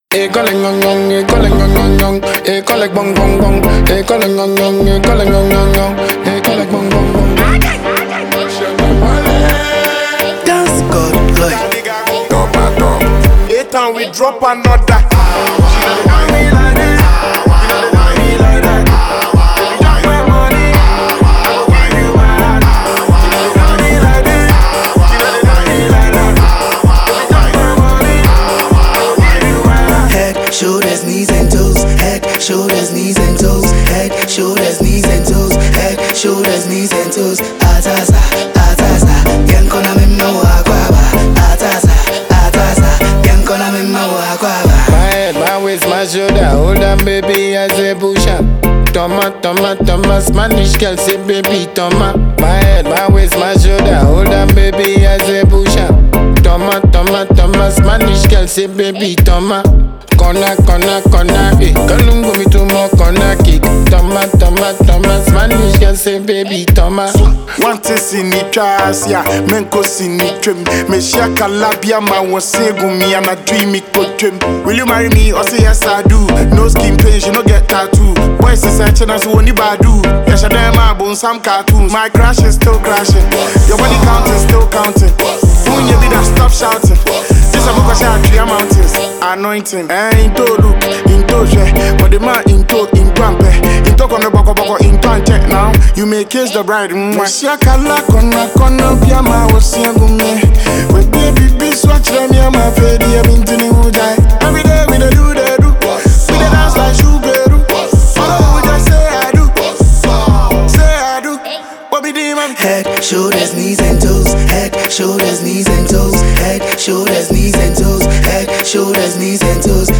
This new track brings even more soul, depth, and energy